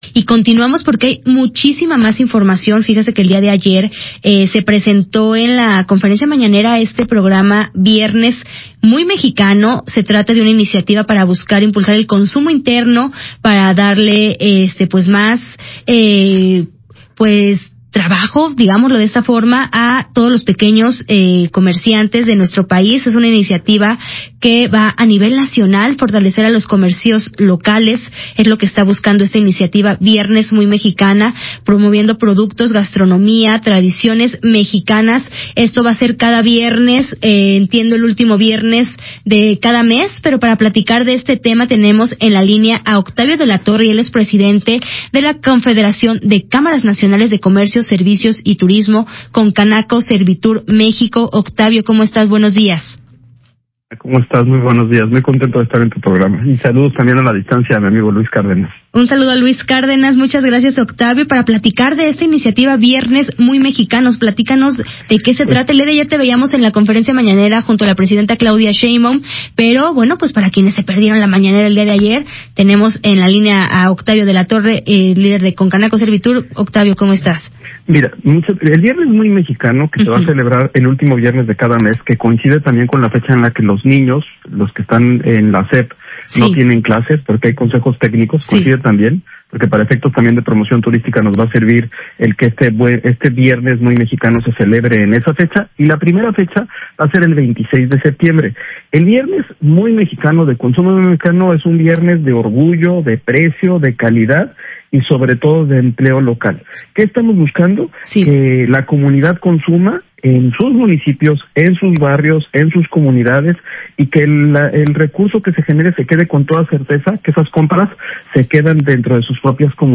ENTREVISTA: MVS Noticias